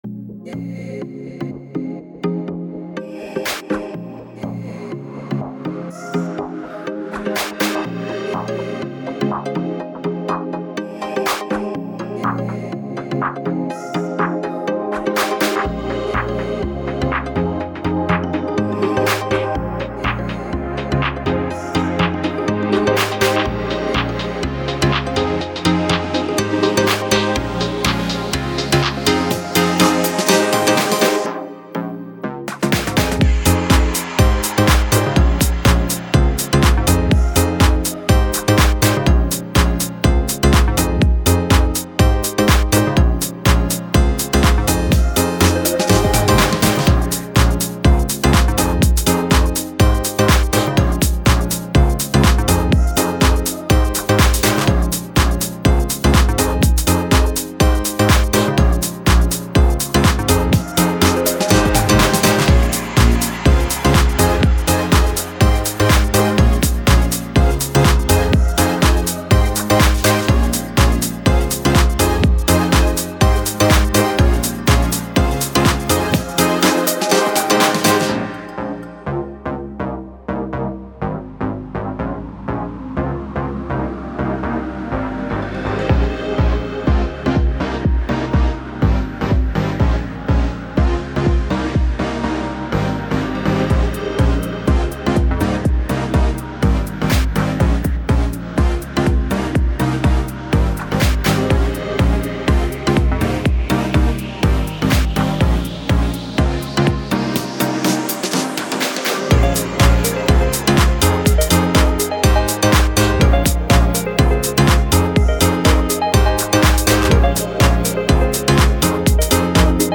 Catchy pop house and dance tracks.